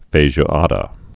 (fāzhdə, -jwädə)